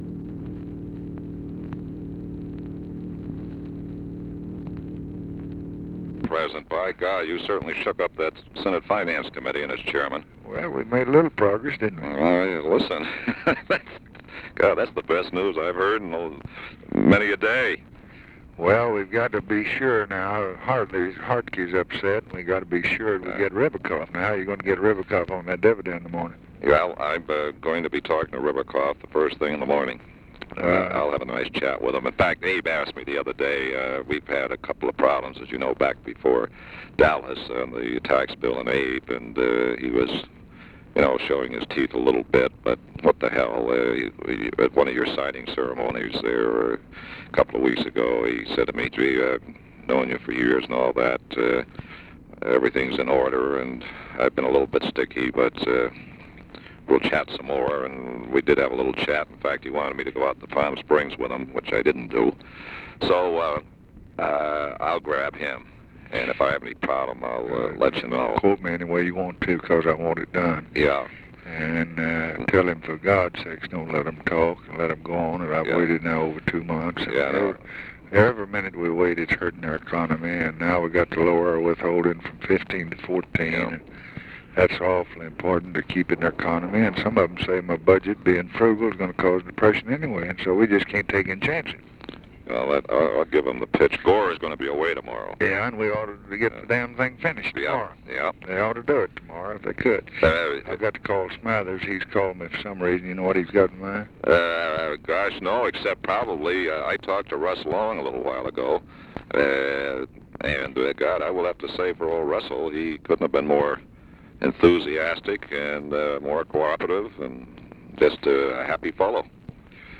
Conversation with LARRY O'BRIEN, January 9, 1964
Secret White House Tapes